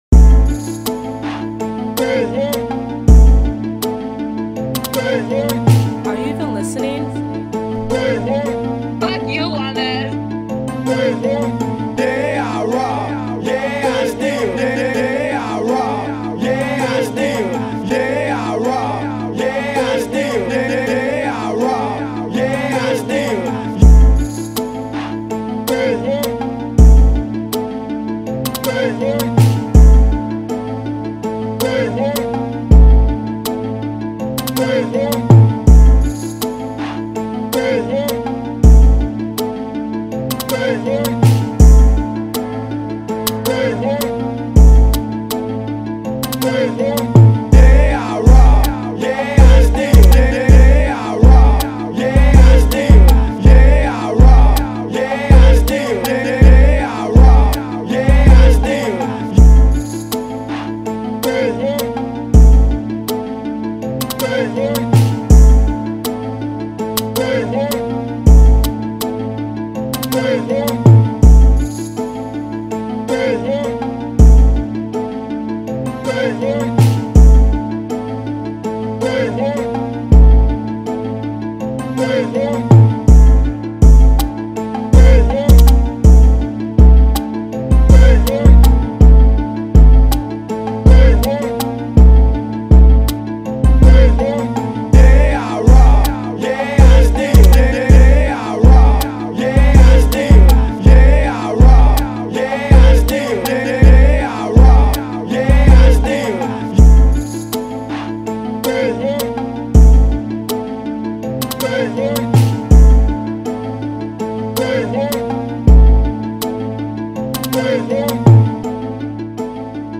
This is the official isntrumental